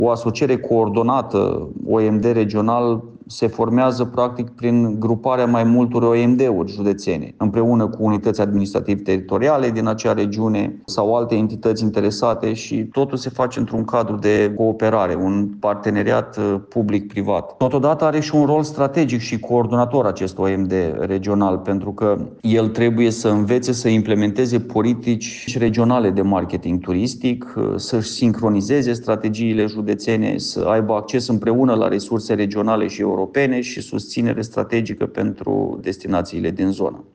Această structură ar însemna cooperare între mai multe județe, un buget de peste 250.000 de euro și politici comune de marketing turistic la nivel regional, a completat viceprimarul Daniel Juravle: